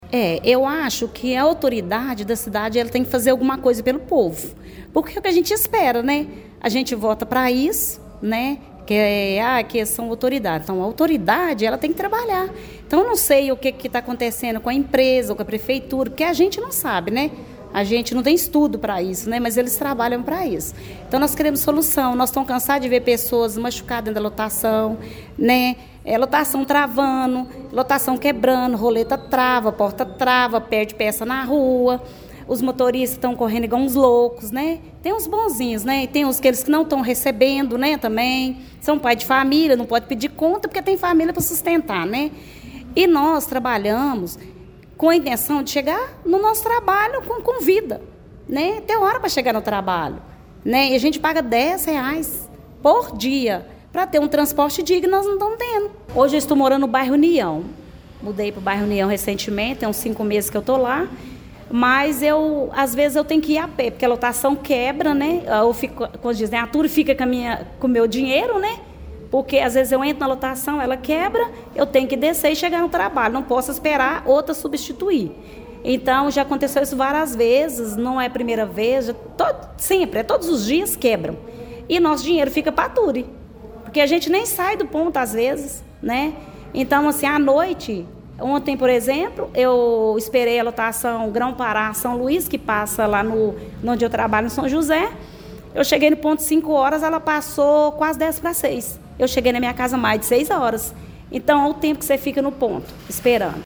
A 7ª Reunião Ordinária da Câmara Municipal de Pará de Minas, realizada nesta terça-feira (03), foi marcada por fortes críticas ao transporte coletivo urbano.